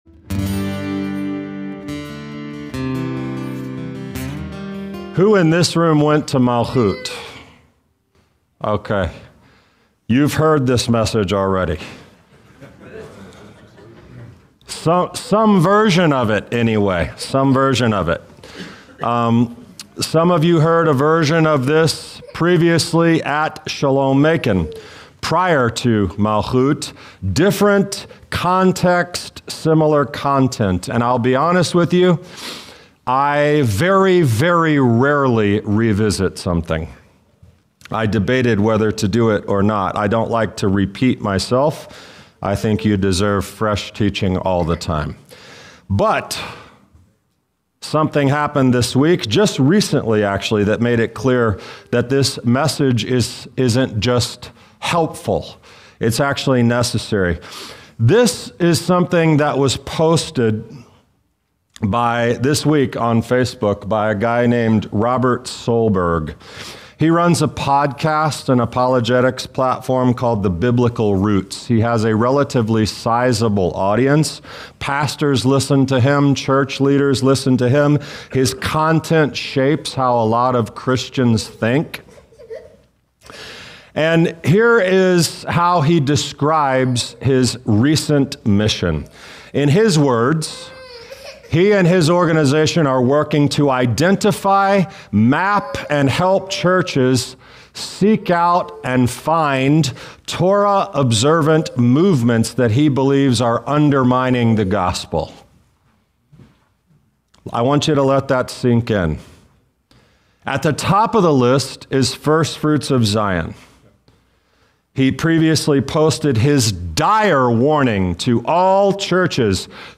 This teaching reframes the journey, the struggle, and your calling.